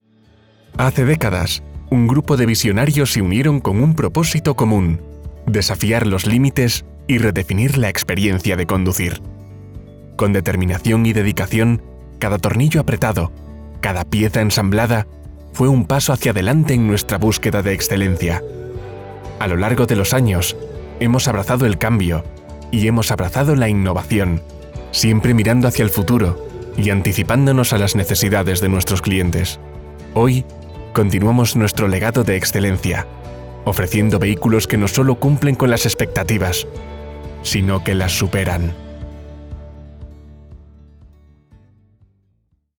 Género: Masculino
Corporativo